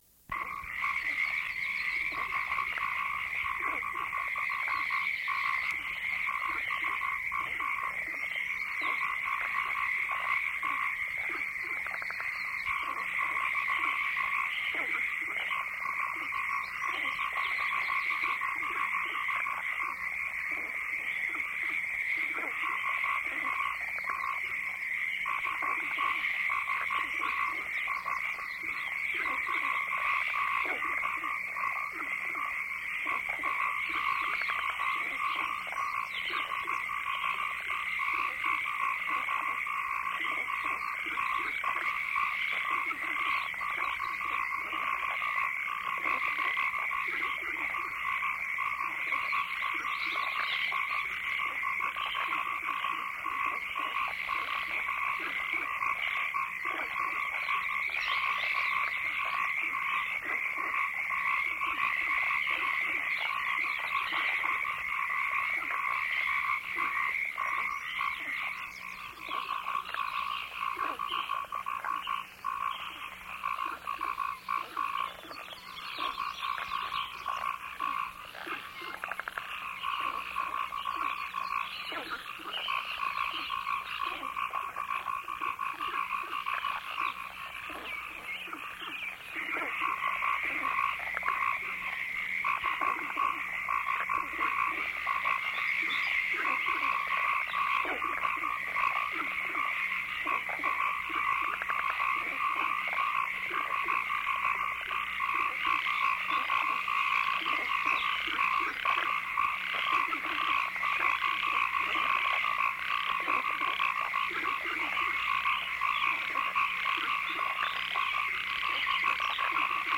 Sempre nel campo del field recording, ecco Sounds of the Everglades, una cassetta di circa 30 minuti registrata nelle paludi della Florida.